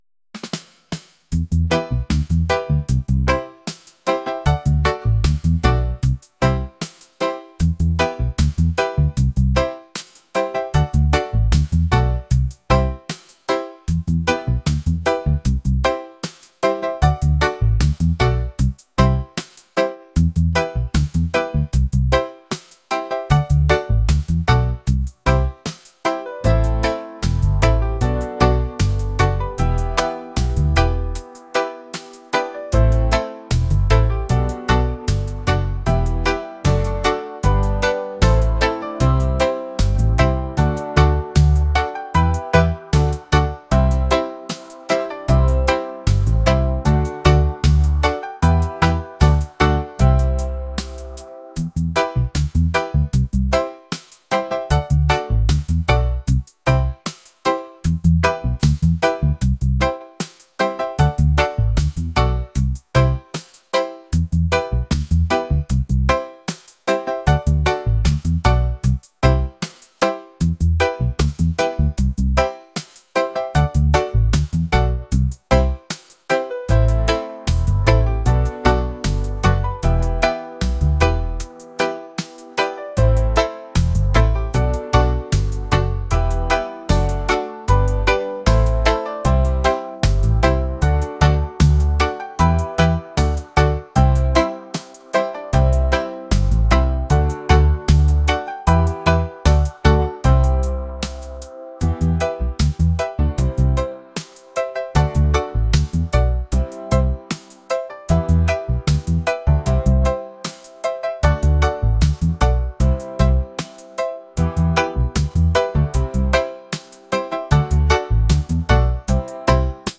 reggae | lofi & chill beats | lounge